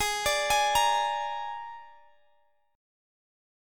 Absus2b5 Chord
Listen to Absus2b5 strummed